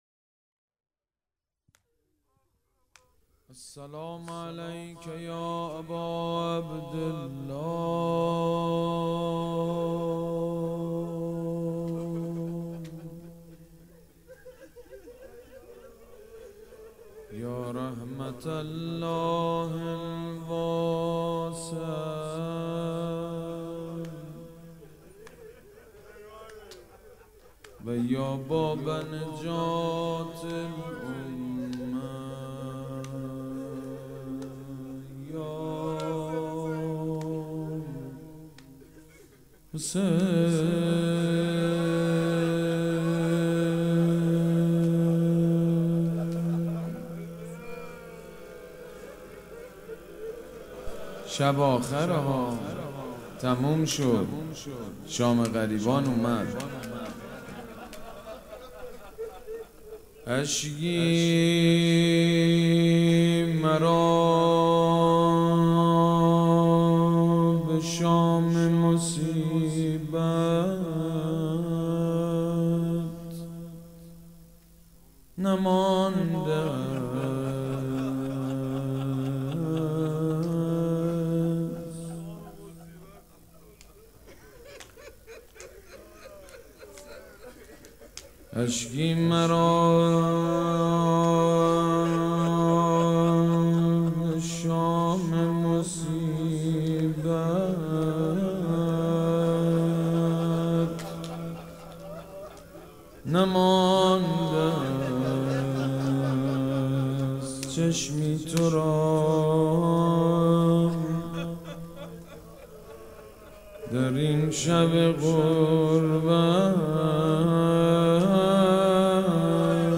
مداحی شام غریبان